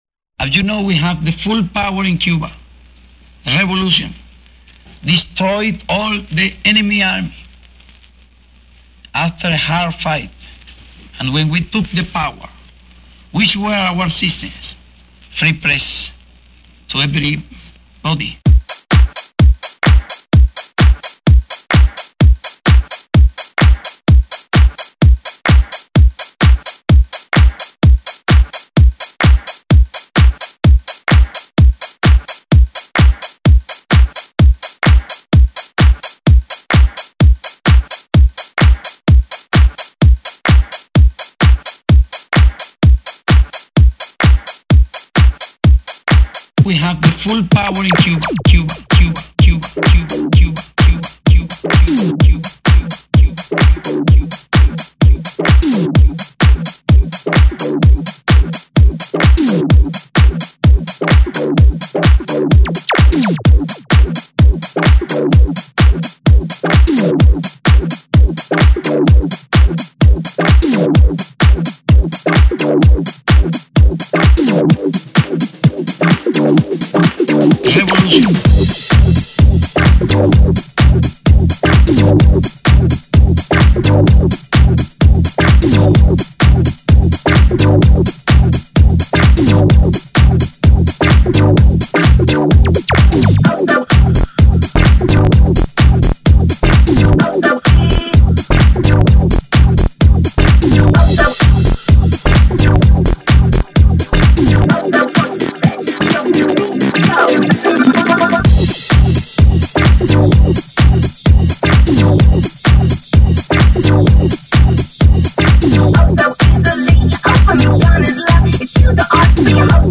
Prague-based, UK-born DJ, producer, remixer and radio jock